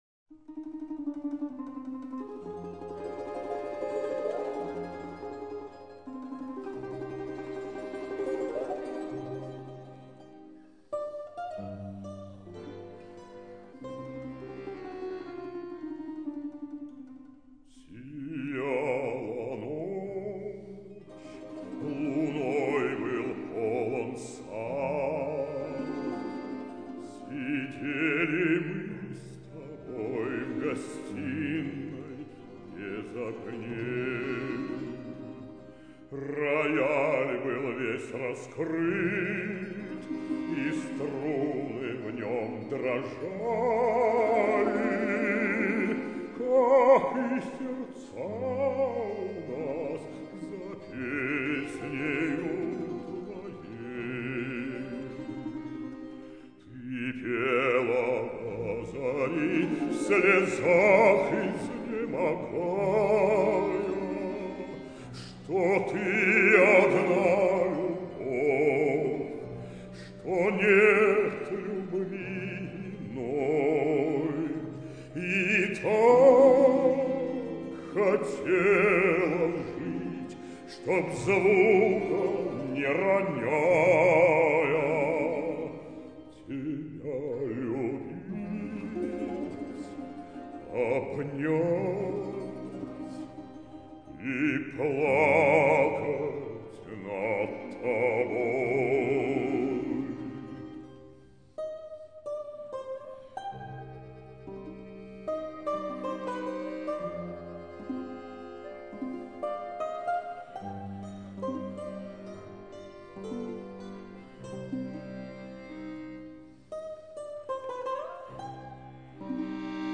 romans.mp3